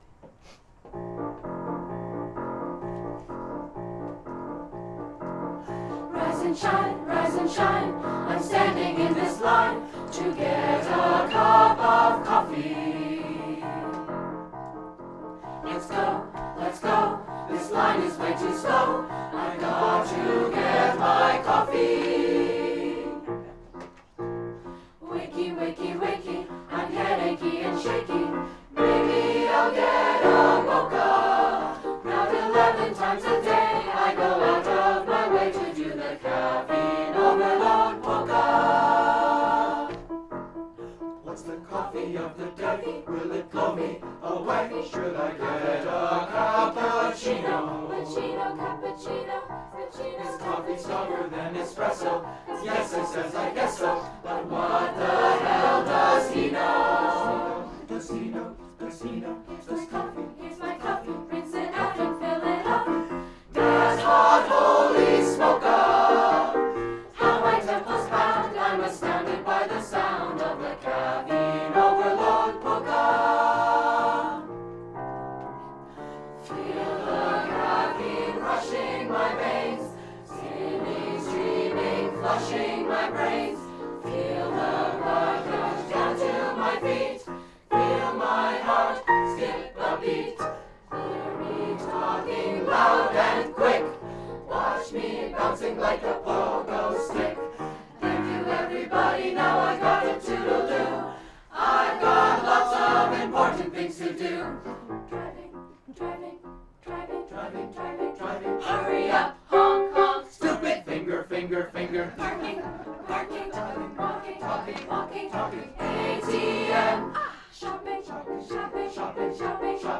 2:00 PM on August 3, 2014, "On the Deck"
Chorus